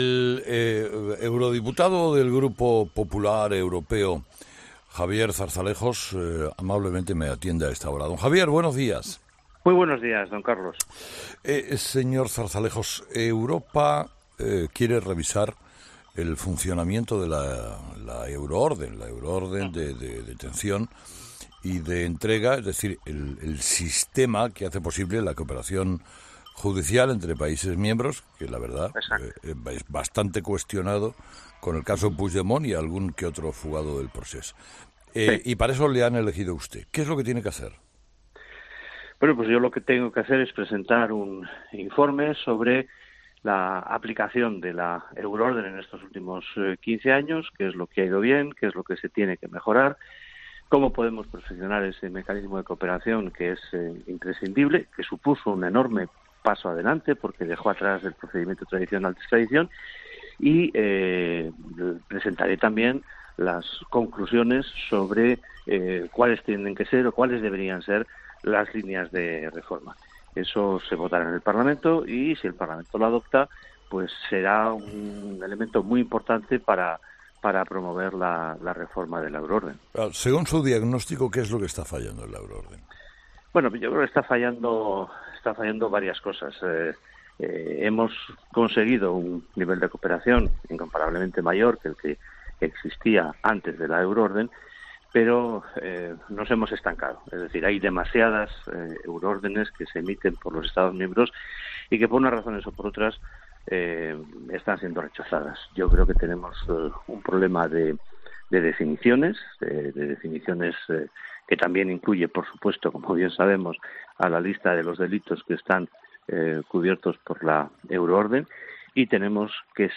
Zarzalejos ha asegurado este jueves en “Herrera en COPE” que “se ha conseguido un nivel de cooperación entre los Estados mayor que antes de la euroorden, sin embargo, nos estamos estancado”.